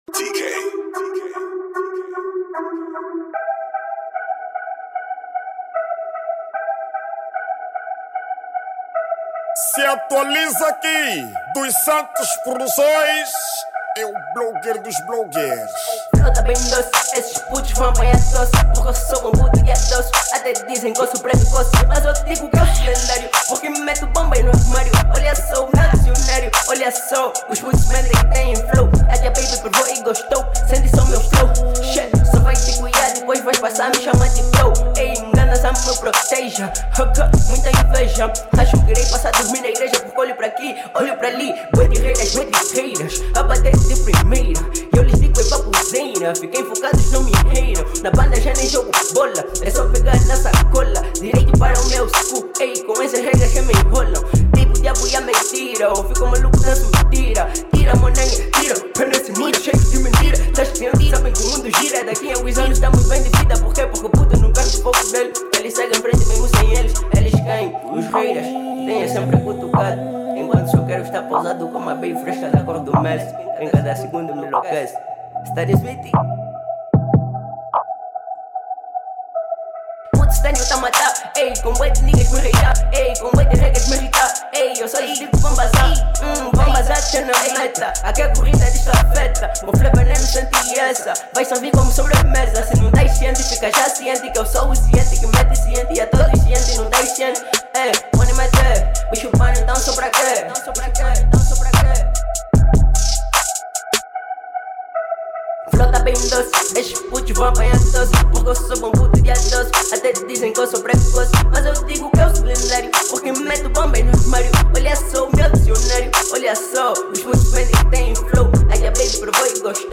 ESTILO DA MÚSICA:  RAP/HIP HOP
MUSIC STYLE: Rap